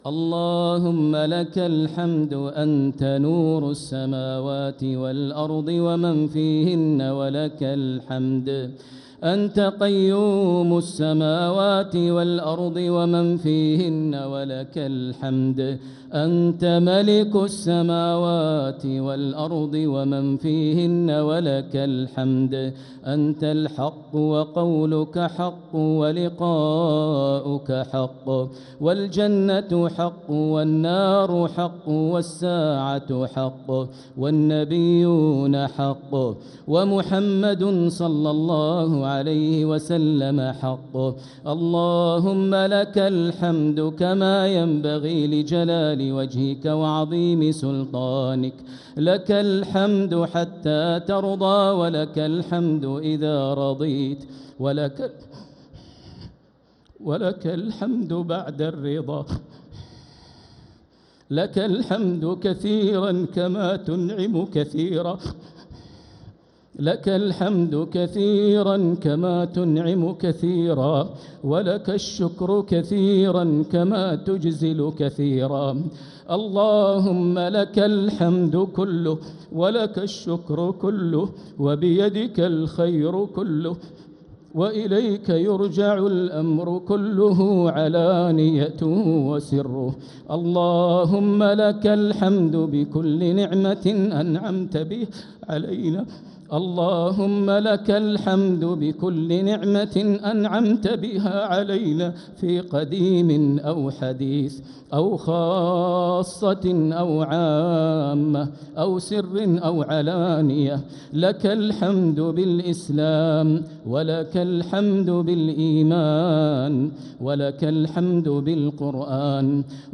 دعاء القنوت ليلة 2 رمضان 1446هـ | Dua 2nd night Ramadan 1446H > تراويح الحرم المكي عام 1446 🕋 > التراويح - تلاوات الحرمين